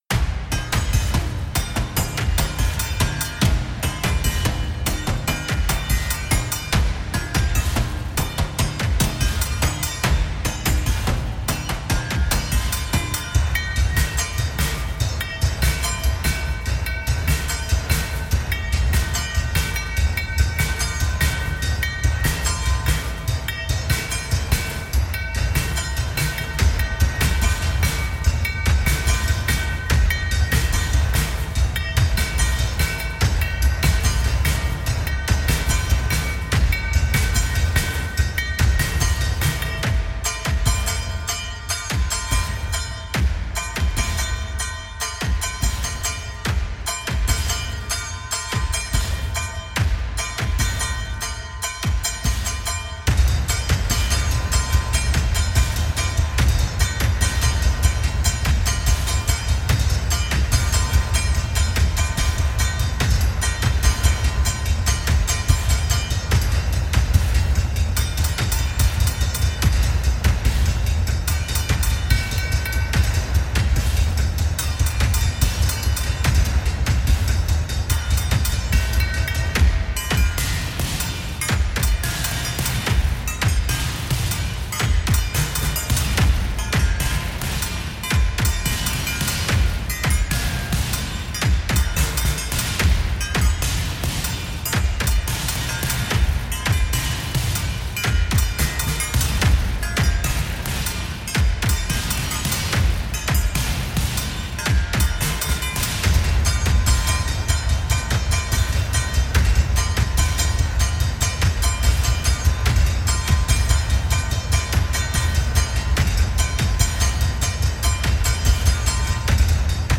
[BPM:145]